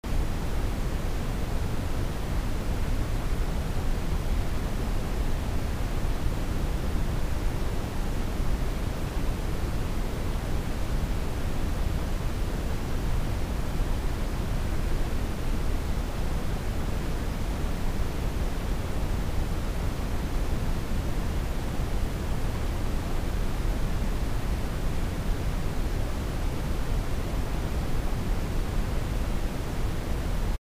На этой странице собраны разнообразные звуки белого шума, включая классическое шипение, помехи от телевизора и монотонные фоновые частоты.
Шум для расслабления